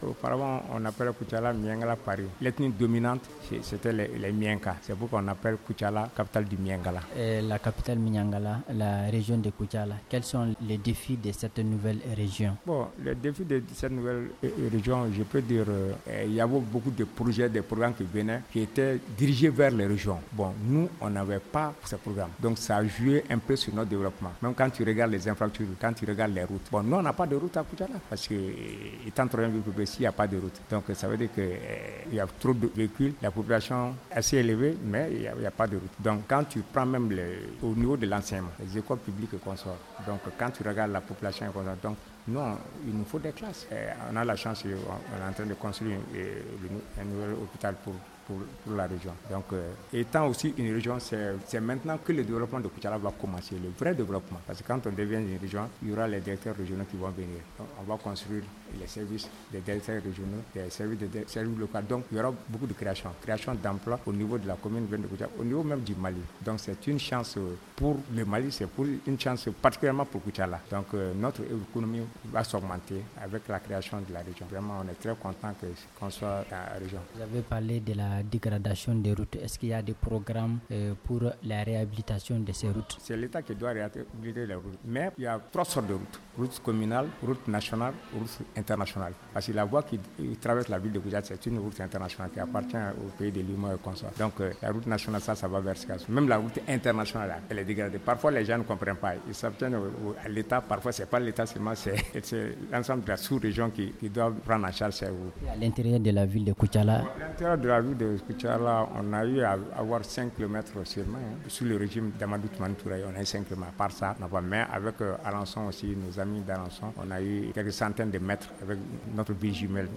Oumar Bah Dembélé, maire de la commune urbaine de Koutiala, répond aux questions